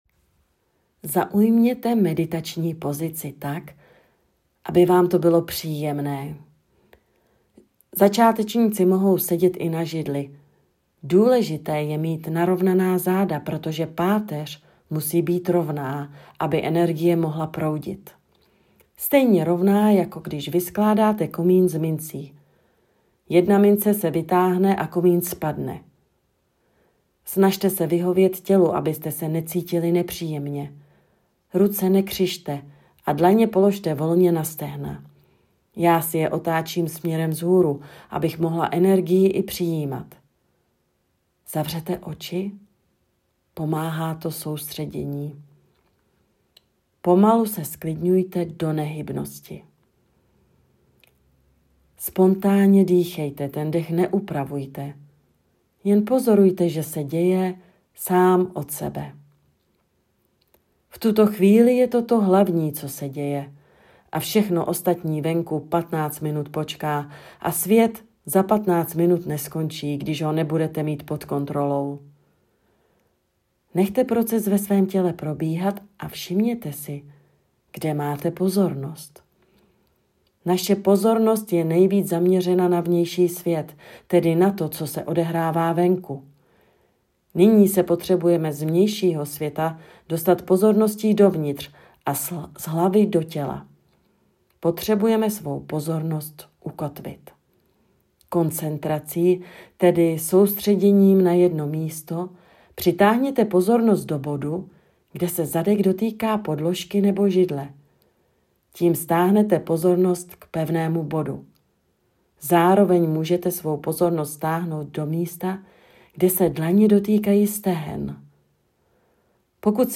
Meditace všímavosti
meditace_vsimavosti.mp3